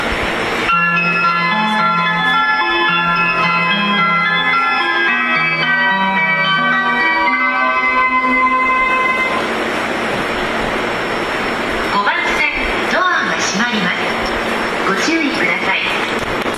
発車メロディをかき消してくれます。
ドアが開いた瞬間に発車メロディが鳴り出すのもこの駅ならではです。